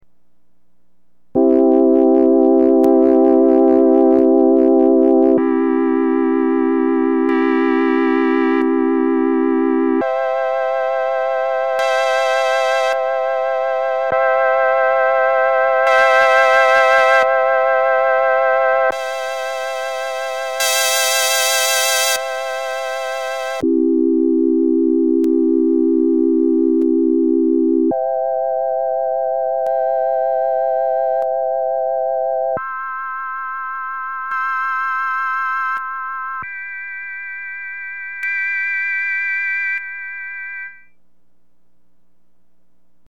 Sounds (These were all done on my Lowrey T2)
Upper Manual Voices
AllVoices:  Starting at the left, I turn on each voice, hold for a moment, turn Brilliance to Full for a moment, then back off. Then I go to the next voice and do the same thing.  Note how the Brilliance has almost no effect on the Flute voices.